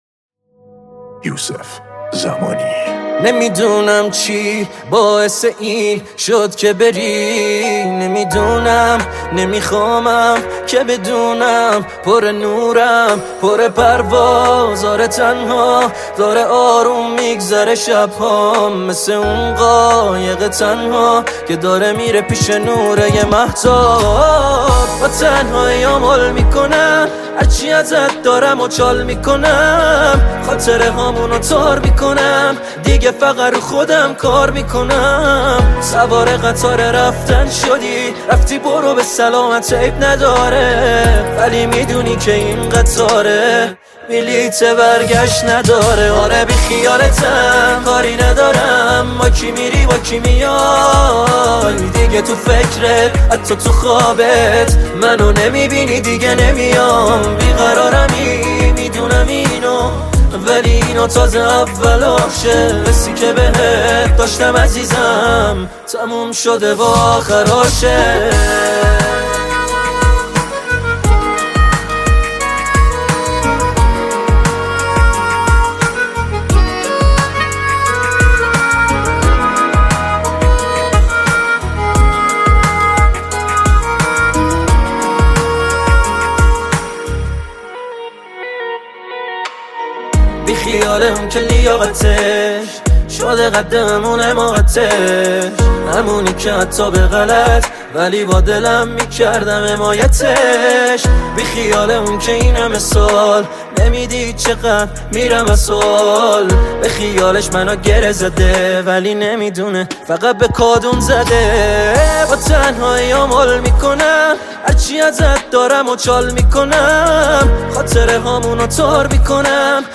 پاپ عاشقانه عاشقانه غمگین